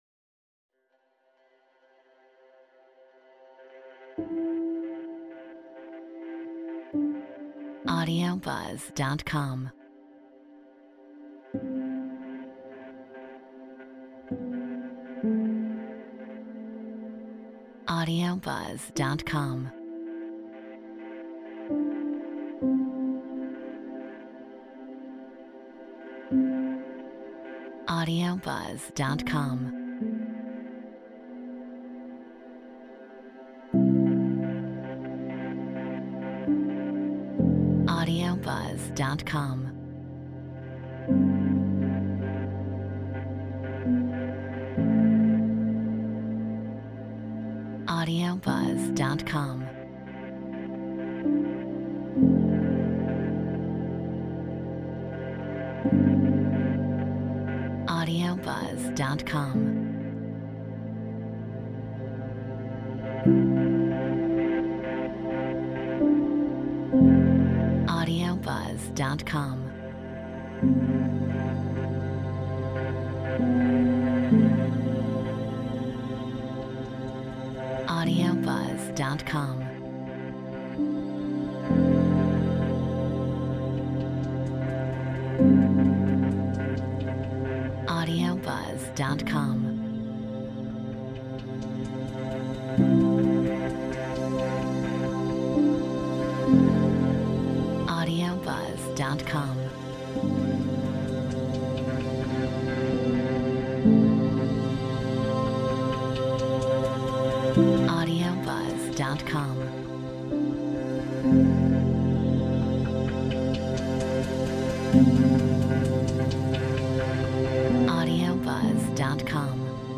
Metronome 65 BPM